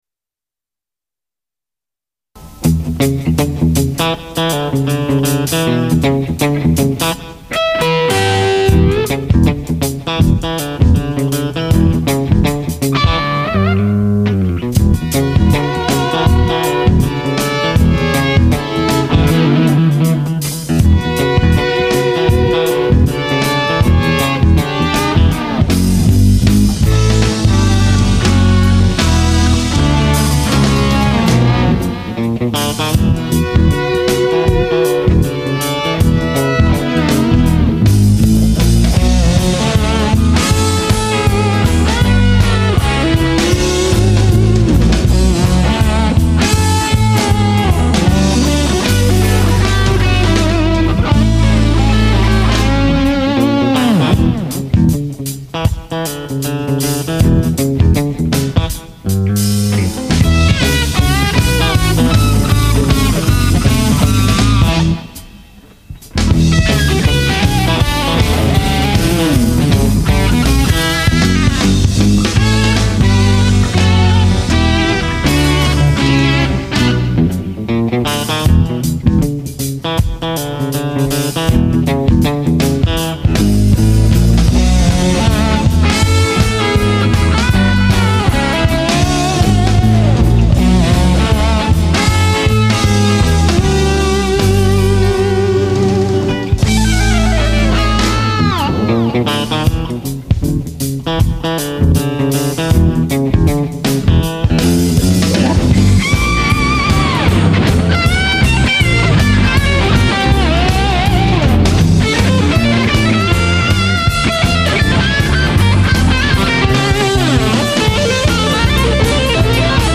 Carvin and 67 Telecaster guitars, bass
Drums